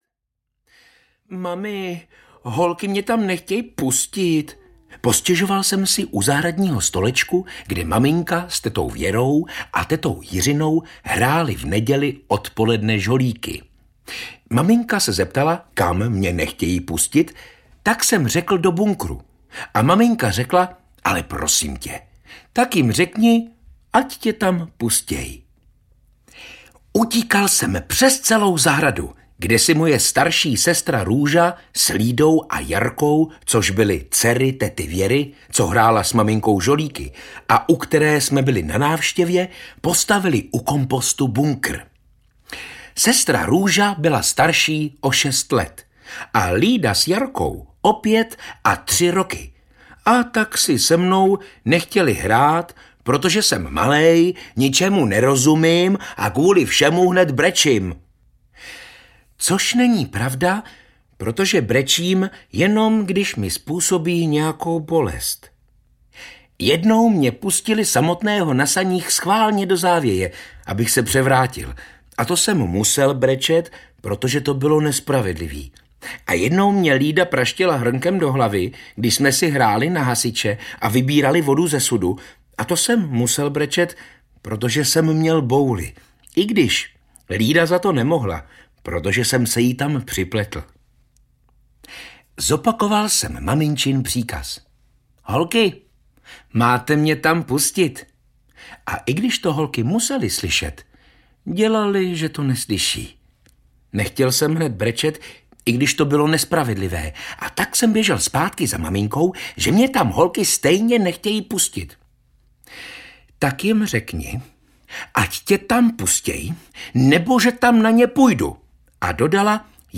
Housle v kravíně audiokniha
Ukázka z knihy